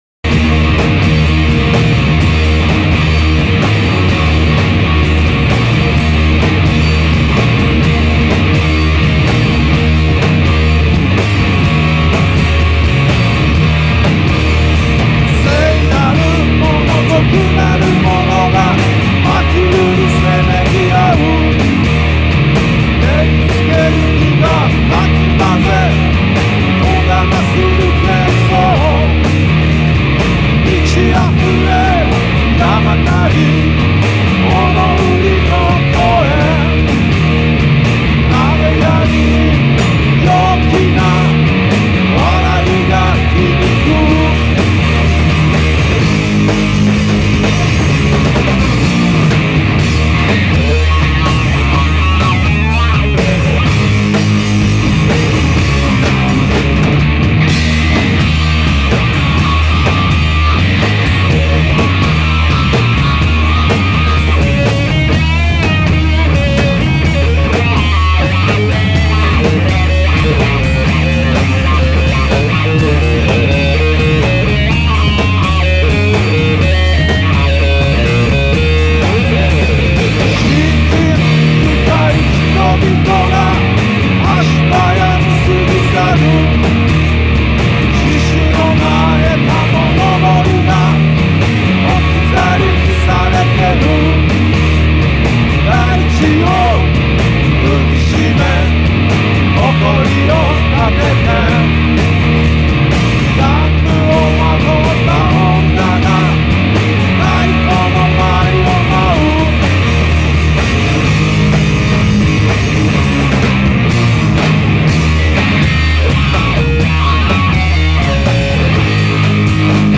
lyrical and hard psychedelic sound